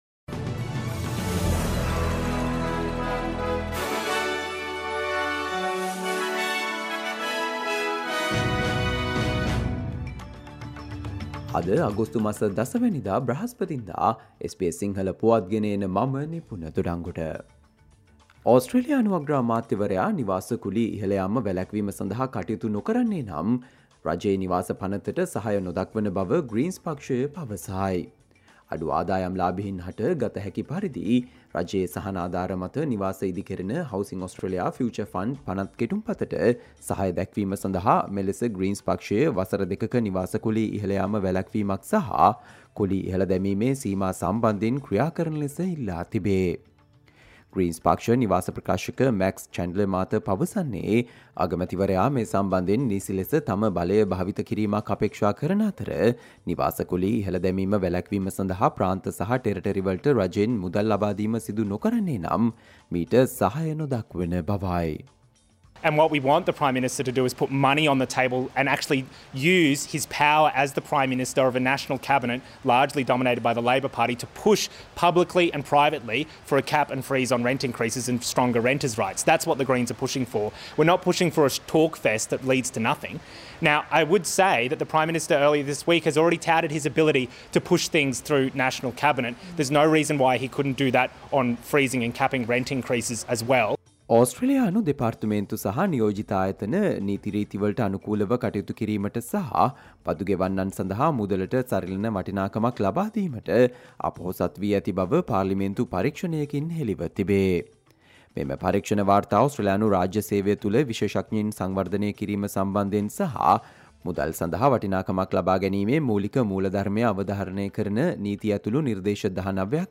Australia news in Sinhala, foreign and sports news in brief - listen, today - Thursday 10 July 2023 SBS Radio News